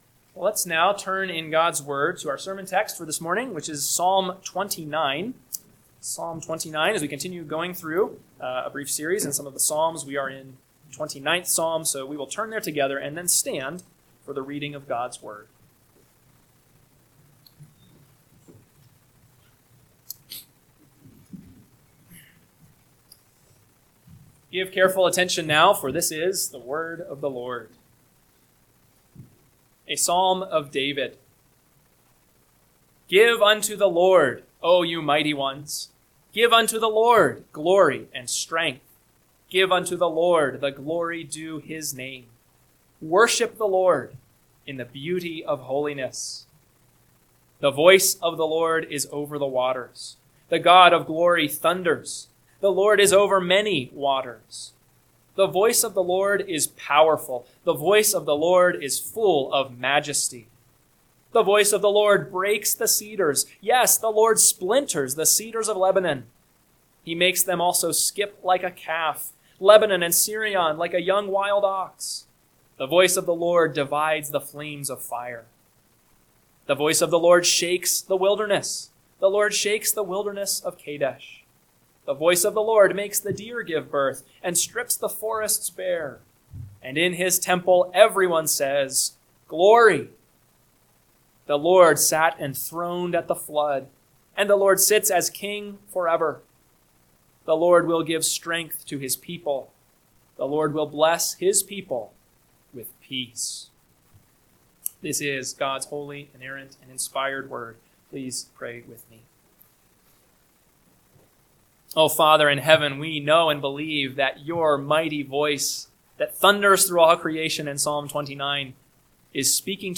AM Sermon – 8/31/2025 – Psalm 29 – Northwoods Sermons